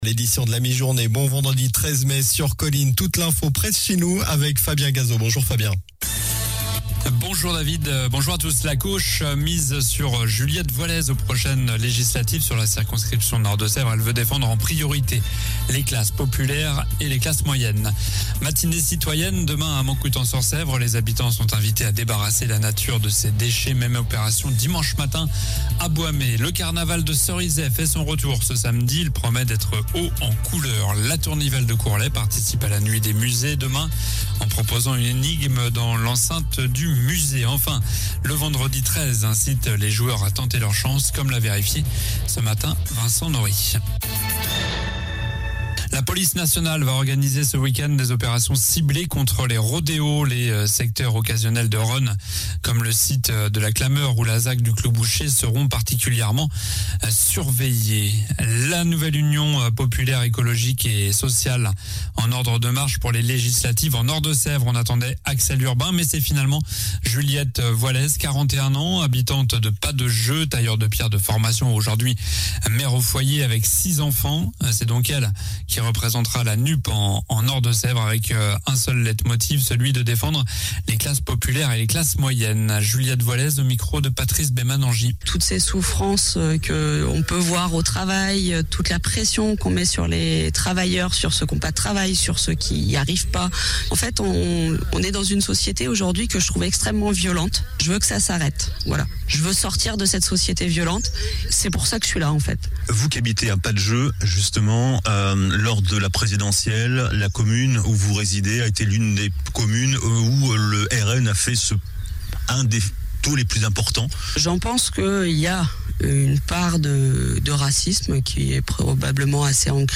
Journal du vendredi 13 mai (midi)